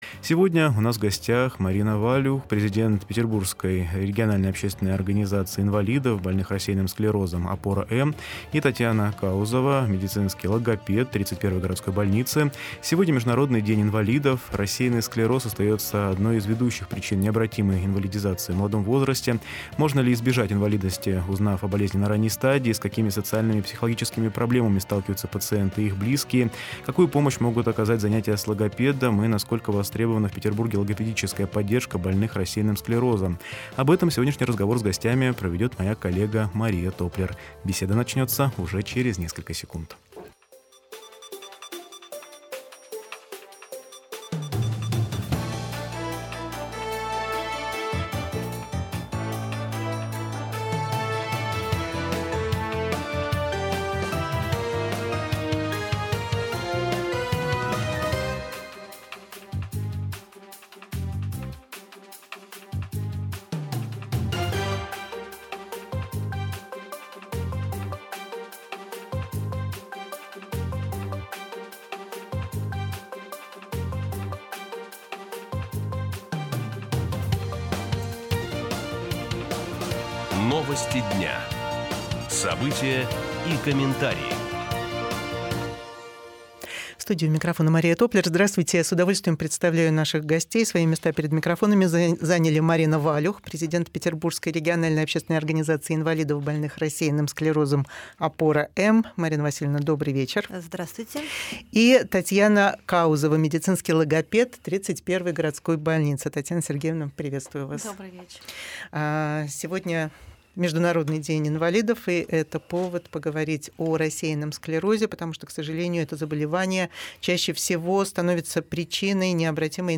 Запись эфира.mp3